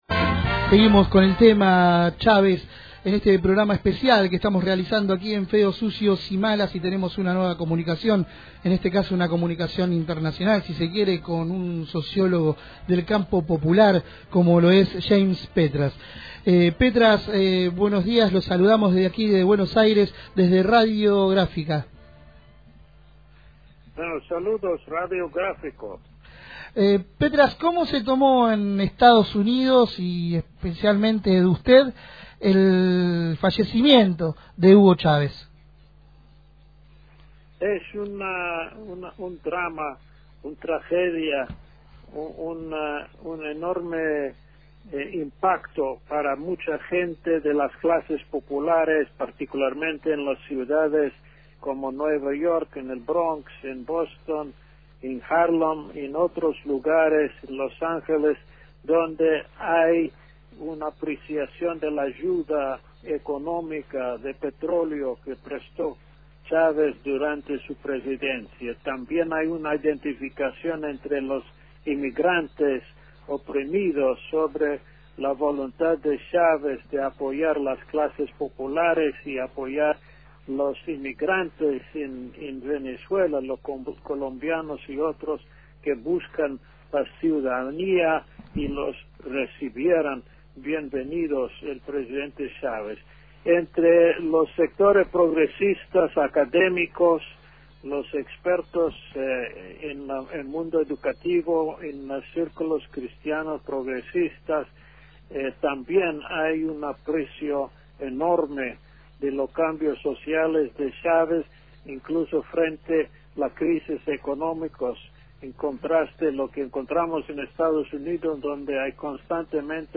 El sociólogo estadounidense James Petras dialogó